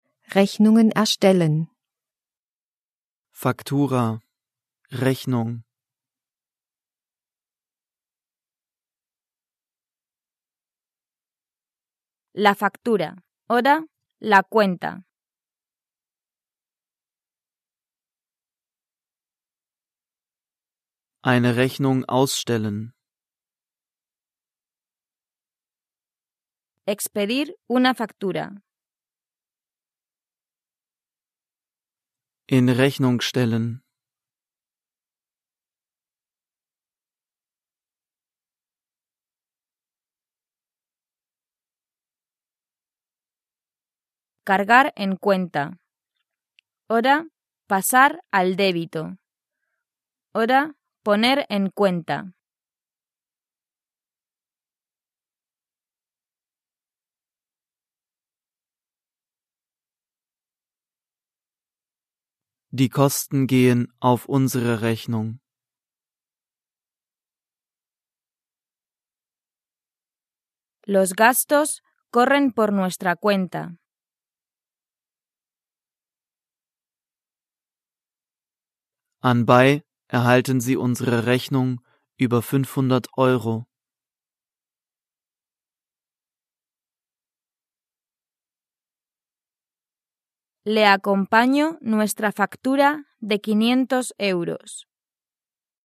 Übersetzungs- und Nachsprechpausen sorgen für die Selbstkontrolle.